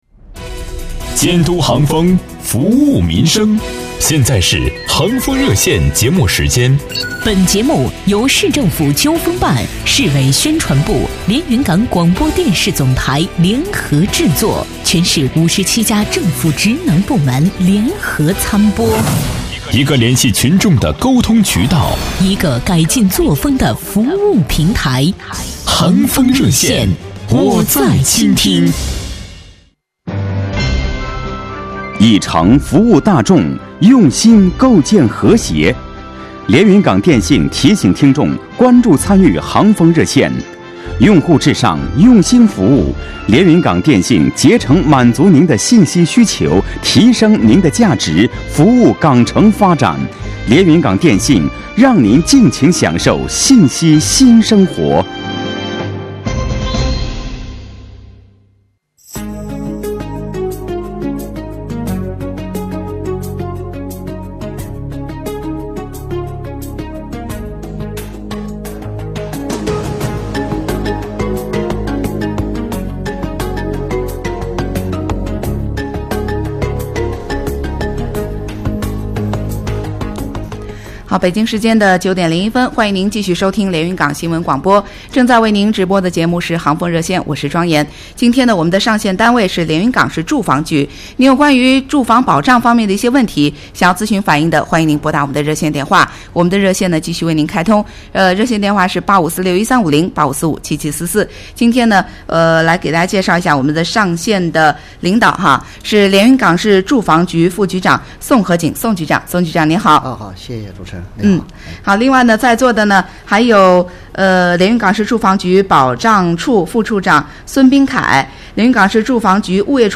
特邀嘉宾 副局长 宋和景